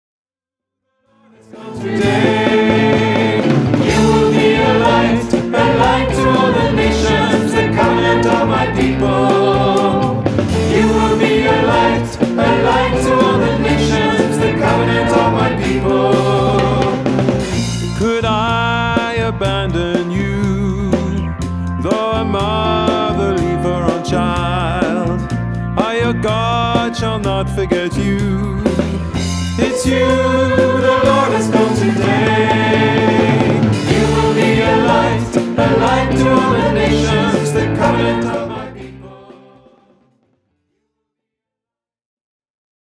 Usage : Louange
MIDI 4 voix